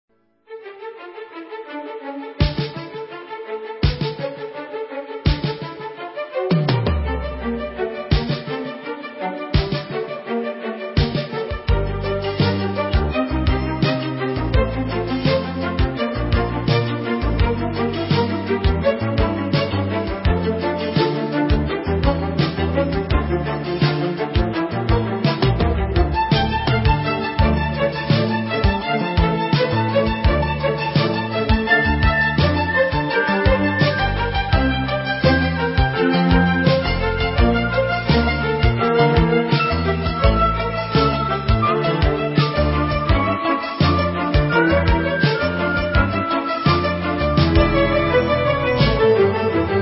в современной обработке